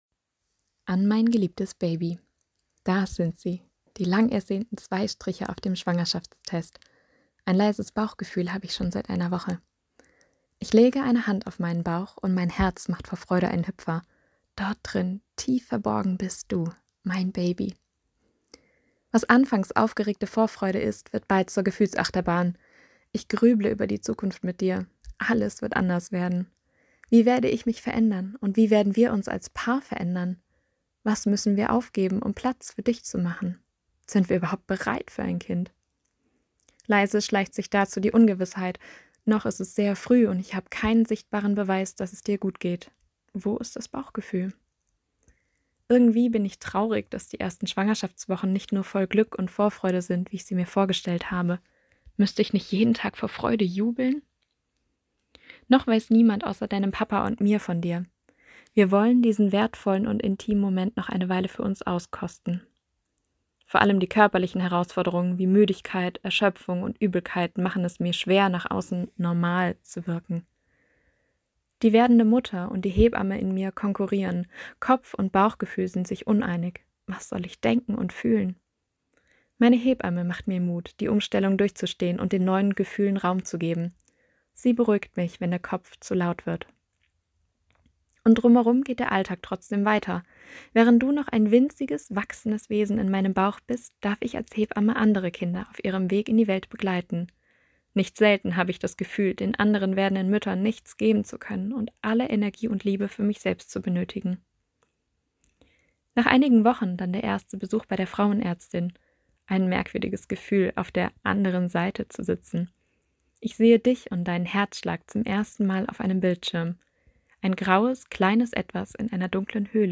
Das geht zusammen – bei uns können Sie Gedichten aus dem jungen forum lauschen. Junge und werdende Hebammen haben ihren Arbeitsalltag lyrisch verarbeitet.